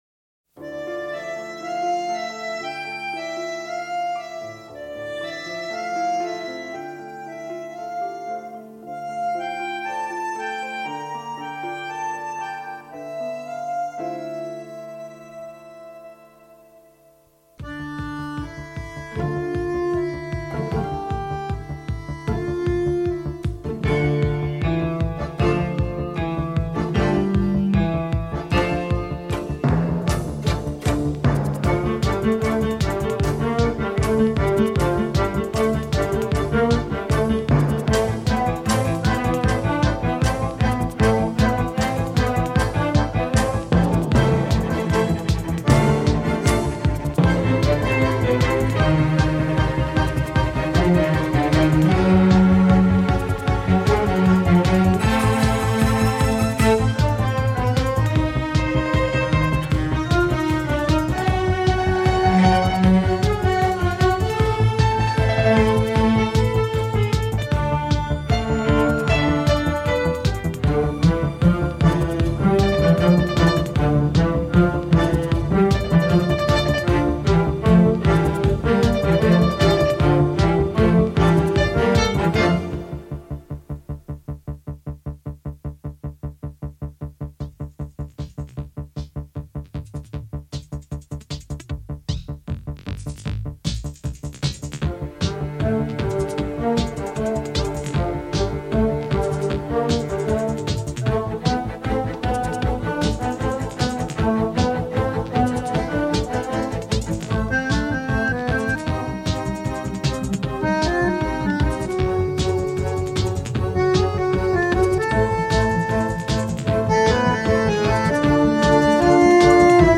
Joli thème nostalgique cependant.